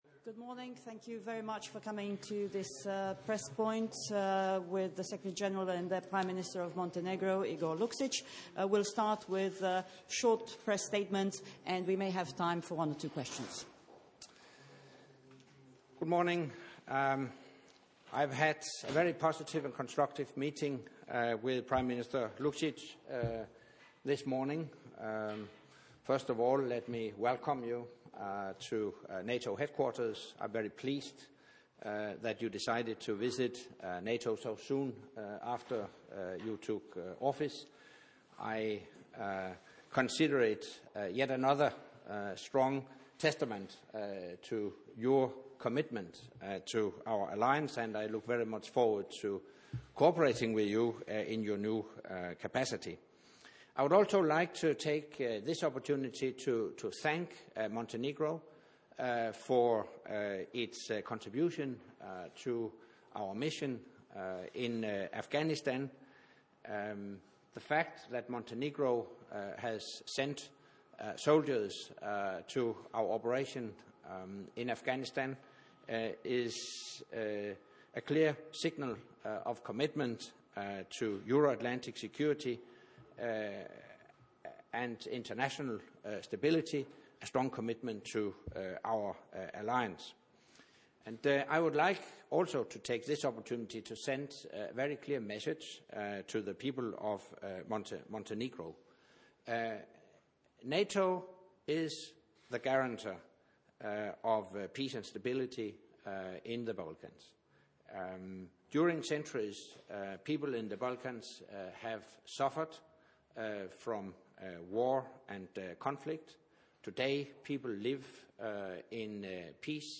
Joint press point with NATO Secretary General Anders Fogh Rasmussen and the Prime Minister of Montenegro Mr. Igor Lukšić (Transcript)